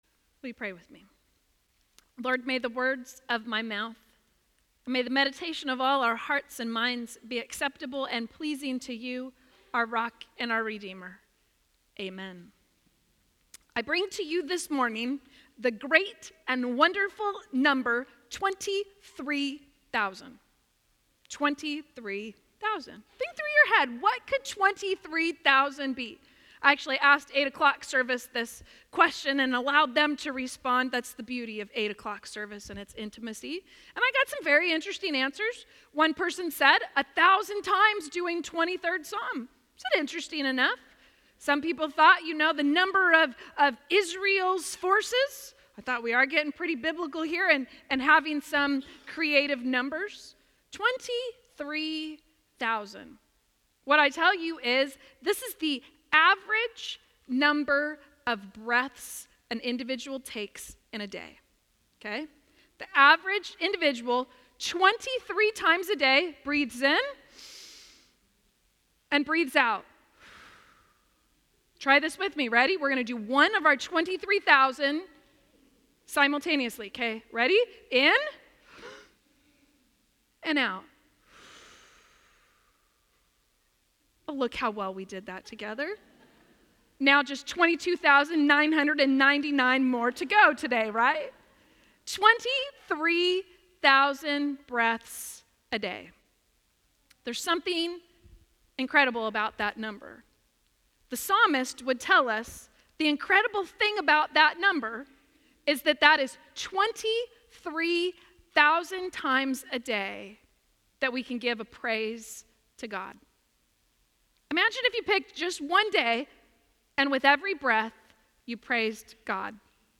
9:30 AM Worship